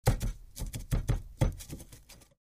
Звук притушивания сигареты в пепельнице